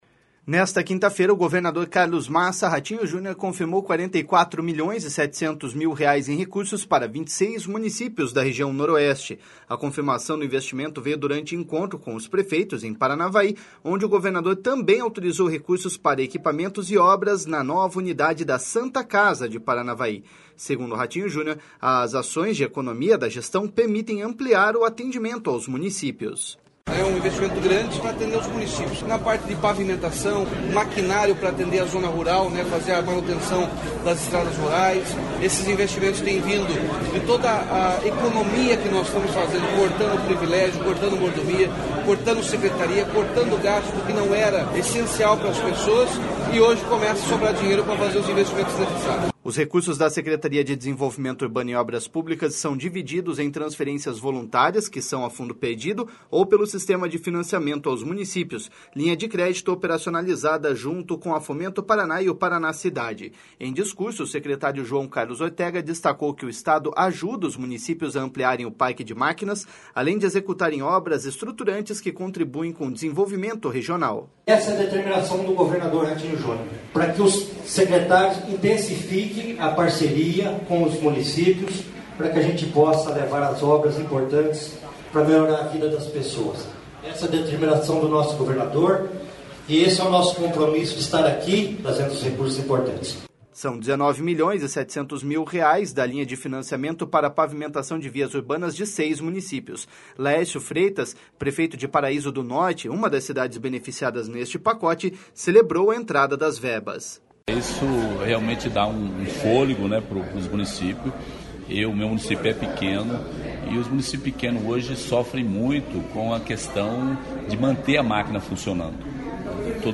Nesta quinta-feira, o governador Carlos Massa Ratinho Junior confirmou 44 milhões e 700 mil reais em recursos para 26 municípios da região Noroeste. A confirmação do investimento veio durante encontro com os prefeitos, em Paranavaí, onde o governador também autorizou recursos para equipamentos e obras na nova unidade da Santa Casa de Paranavaí.
Em discurso, o secretário João Carlos Ortega destacou que o Estado ajuda os municípios a ampliarem o parque de máquinas, além de executarem obras estruturantes que contribuem com o desenvolvimento regional.// SONORA JOÃO CARLOS ORTEGA.//